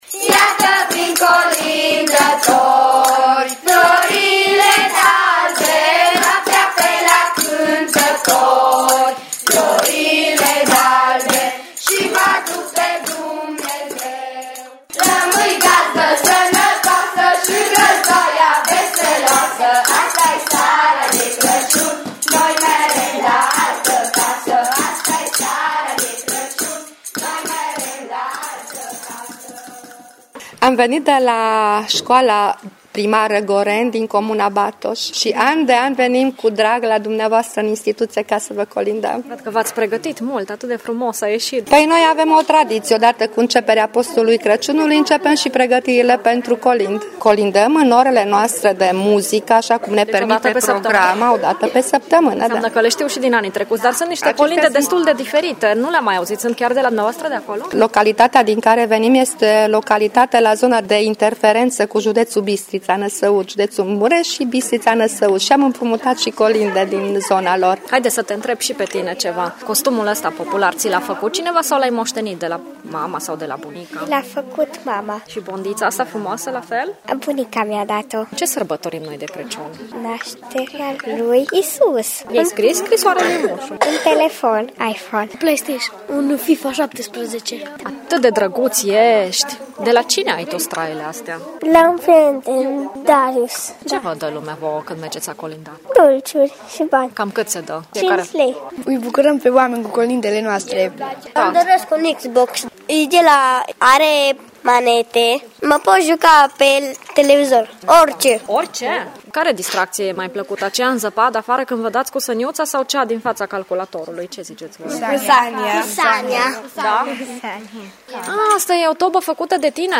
Colinde foarte rare, de prin zona Batoșului și porturi la fel de rare, moștenite de la bunici și părinți.
Un reportaj